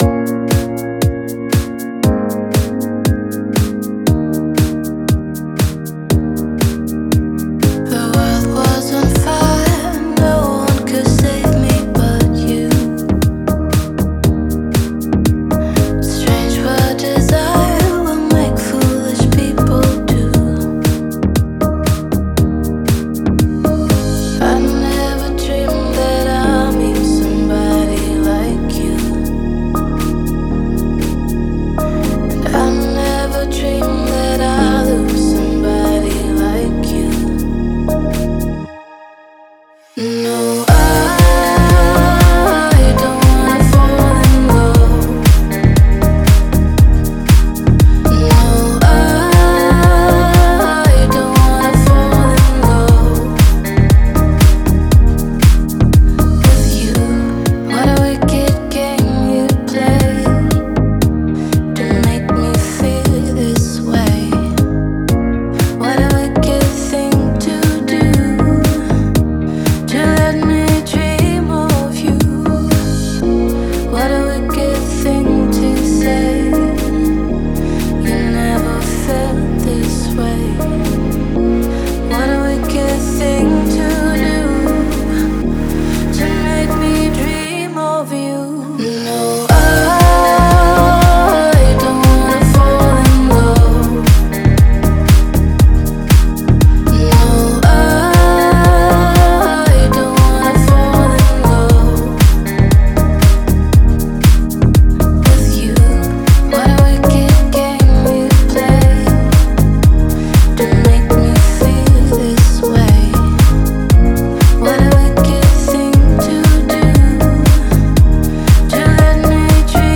Эмоциональные вокалы
современными электронными элементами